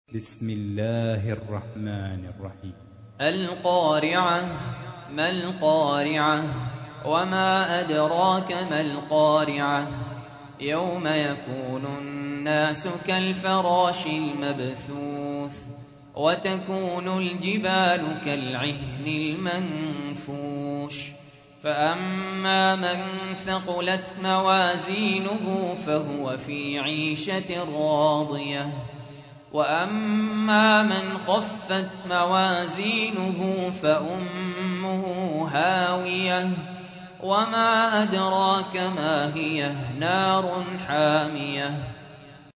تحميل : 101. سورة القارعة / القارئ سهل ياسين / القرآن الكريم / موقع يا حسين